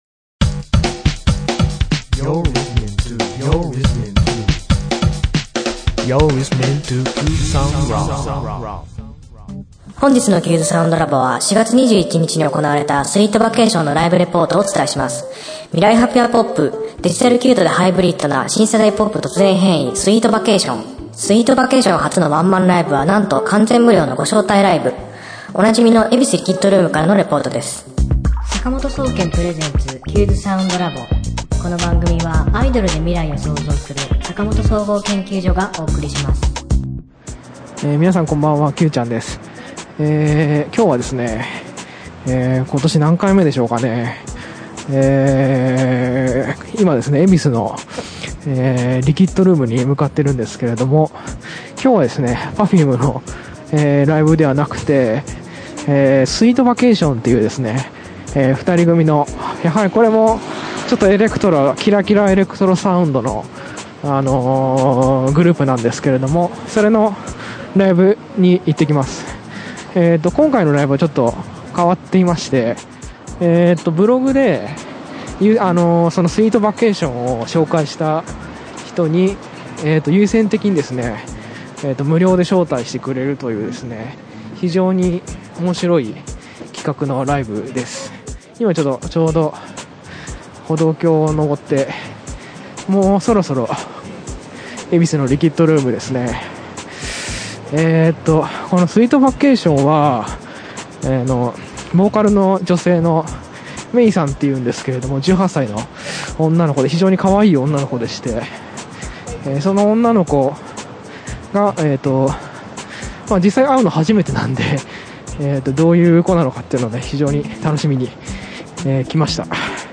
今週のテーマ：“Sweet Vacation”初のワンマンライブの様子をレポート！
おなじみの恵比寿リキッドルームからのレポートです。